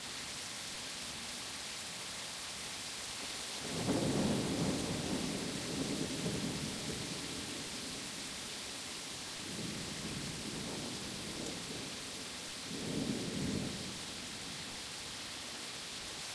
rain.ogg